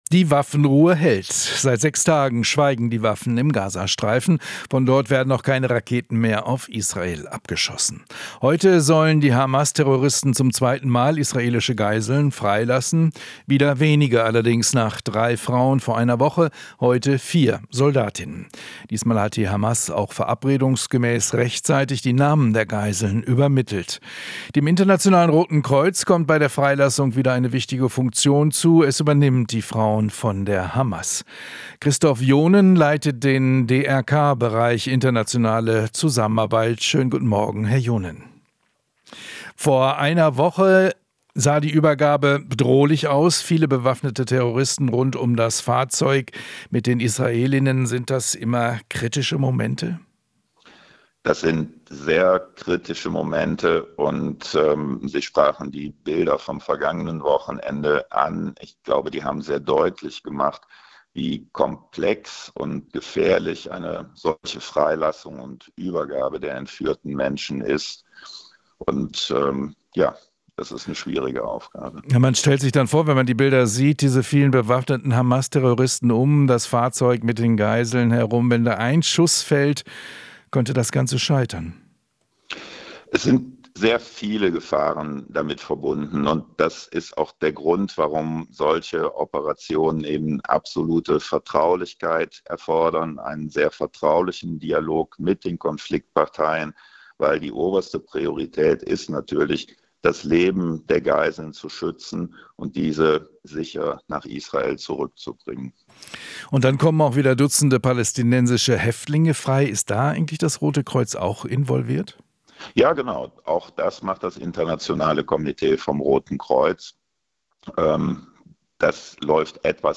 Interview - DRK: Humanitäre Lage in Gaza weiterhin verheerend